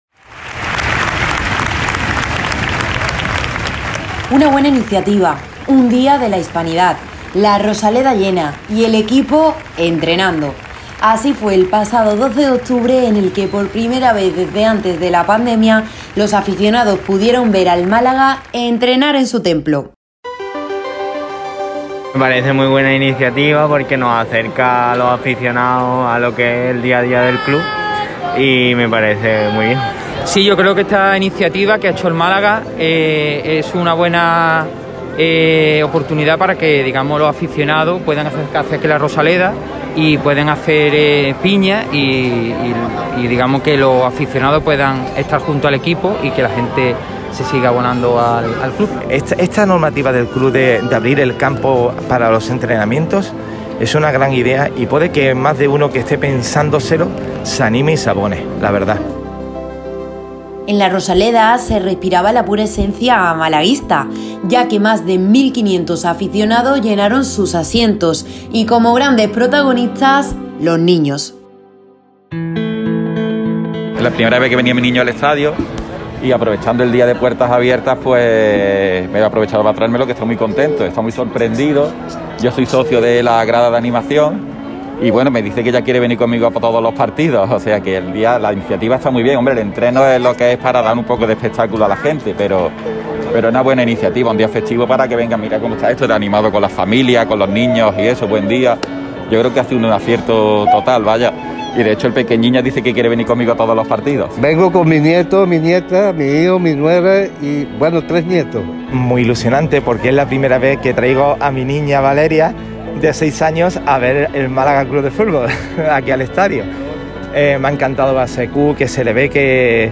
La afición malaguista habla desde el entrenamiento - Radio Marca Málaga
Así se vivió el entrenamiento a puertas abiertas del Málaga CF en La Rosaleda
Con motivo del primer entreno a puertas abiertas del Málaga CF, Radio Marca Málaga se desplazó al templo para vivirlo en primera persona con los aficionados. Una iniciativa del club a la que acudieron más de 1.500 malaguistas y que estuvo claramente protagonizada por los niños.
REPORTAJE-ENTRENAMIENTO-AFICION.mp3